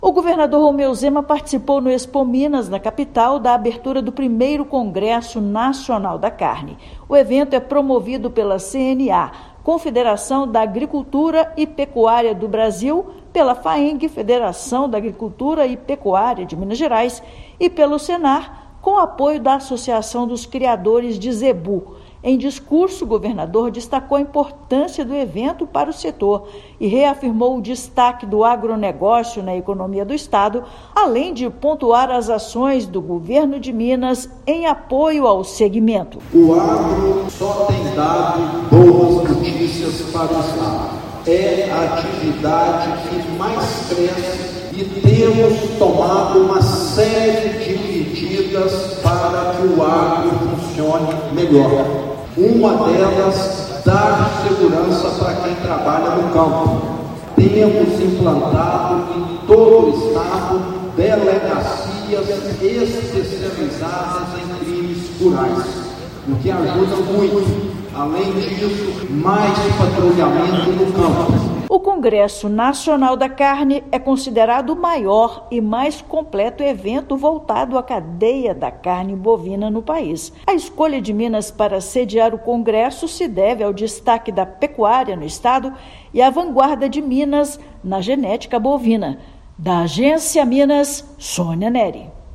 Estado foi escolhido para sediar o evento por seu destaque na pecuária e vanguarda na genética bovina. Ouça matéria de rádio.